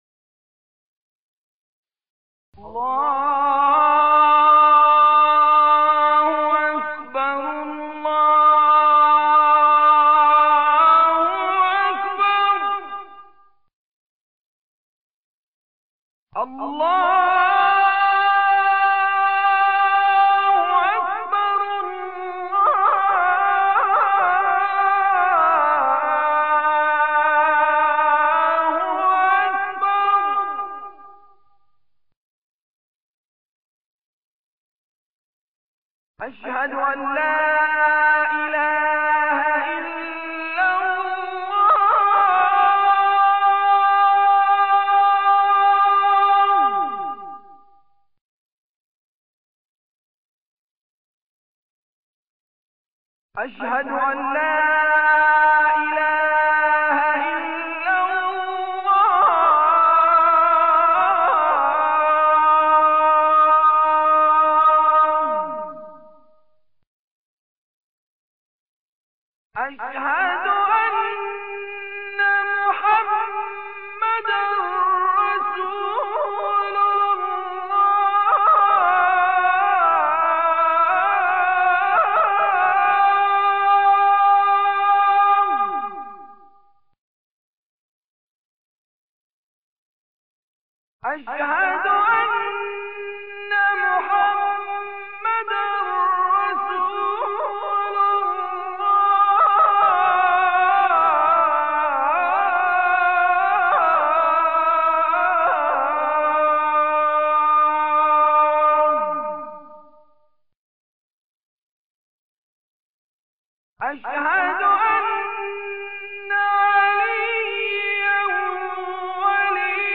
اذان